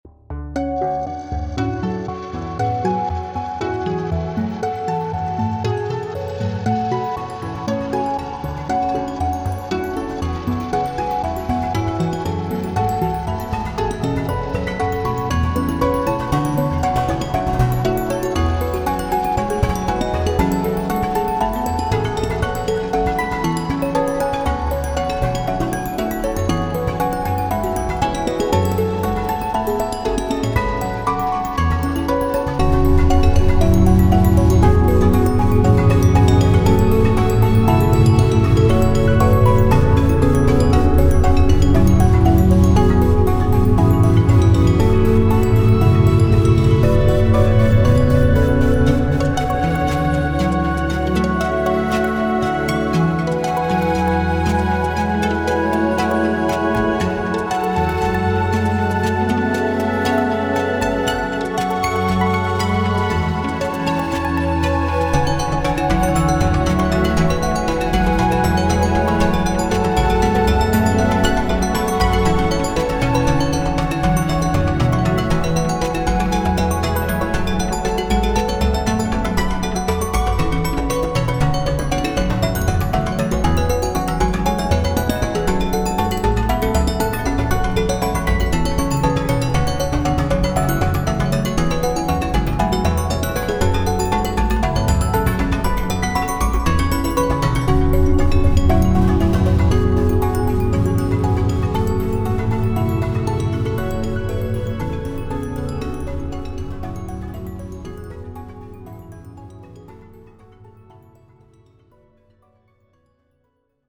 クリスタルの洞窟をイメージしたBGM。
タグ: ファンタジー フィールド楽曲 冒険 地下/洞窟 幻想的 コメント: クリスタルの洞窟をイメージしたBGM。